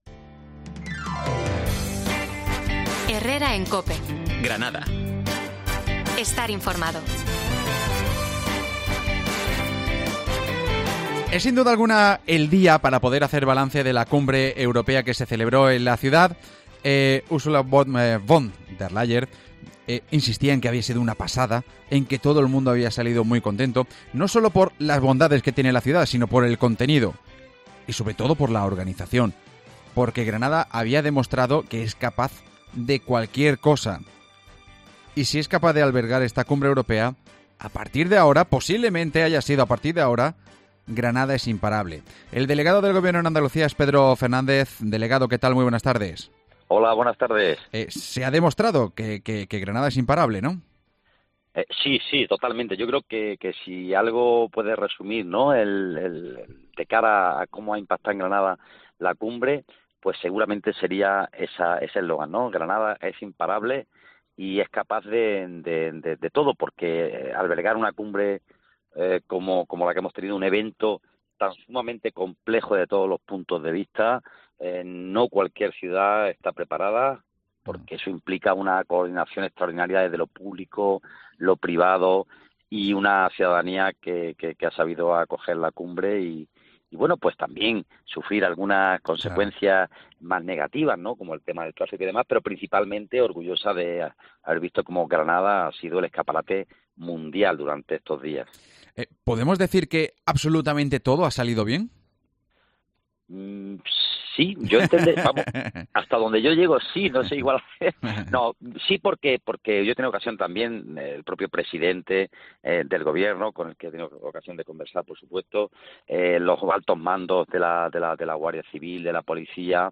Pedro Fernández, delegado del gobierno en Andalucía nos da los detalles de los preparativos que han durado un año